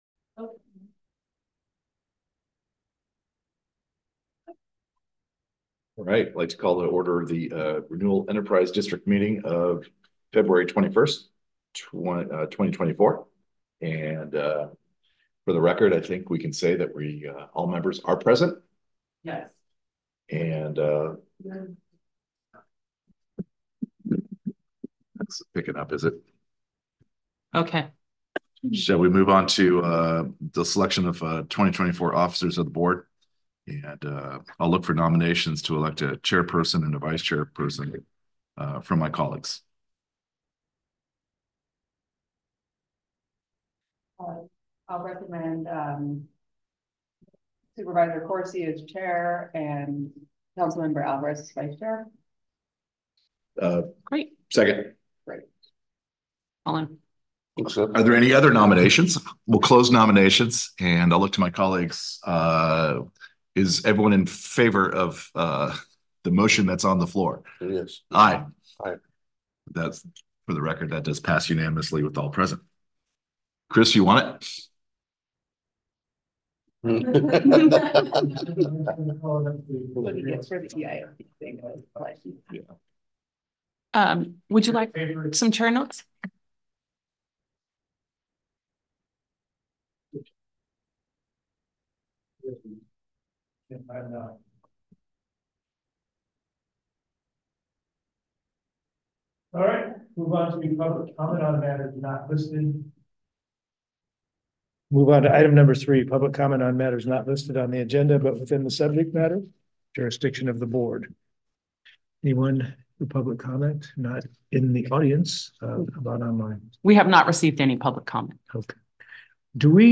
Board Meeting
Agenda Meeting Packet Meeting Recording Meeting Minutes PLEASE NOTE: This is a hybrid meeting and will be held in-person and online. The meeting will be held at the Sonoma County Transportation Authority Hearing Room, 411 King Street, Santa Rosa, CA, 95404. Public Comment may be made live, in person.